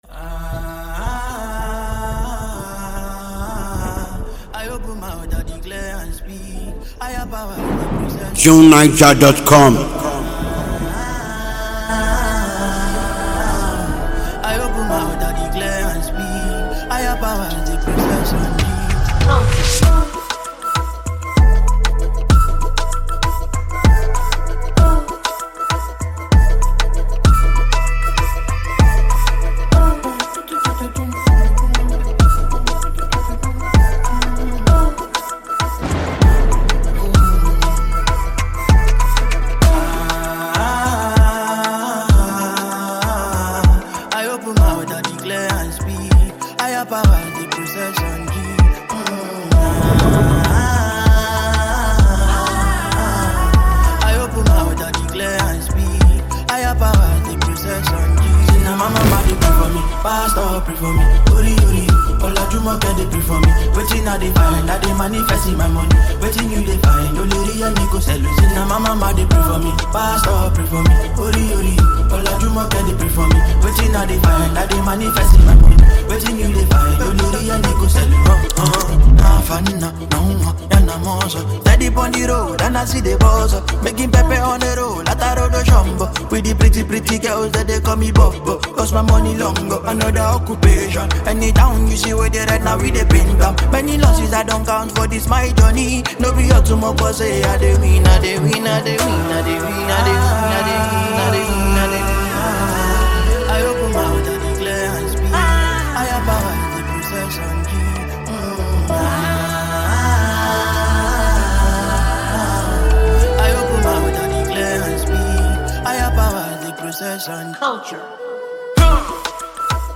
a talented and adaptable Nigerian singer and songwriter